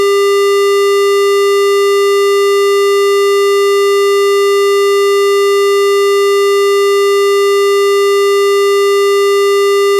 400hz square.wav